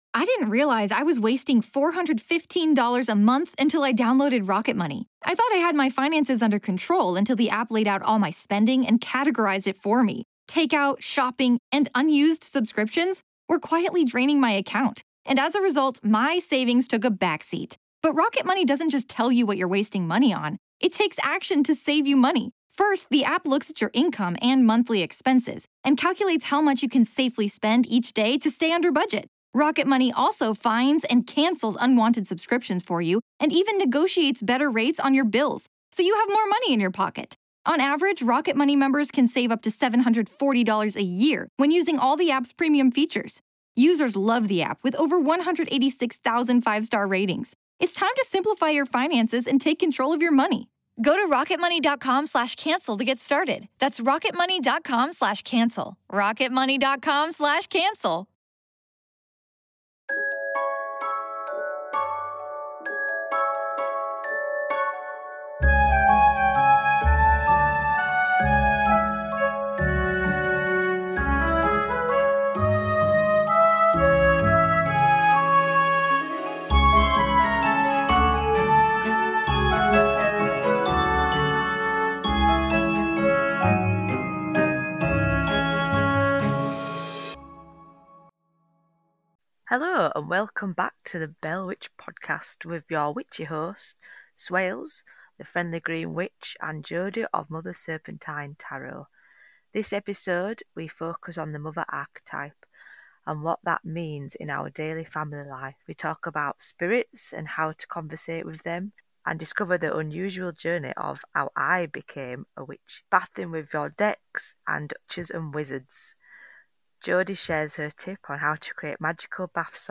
Pour yourself a brew or a glass of mead, come sit with us two northern birds and have a laugh as we go off on tangents. Expect laughter, soothing Yorkshire Mum sofa chat vibes.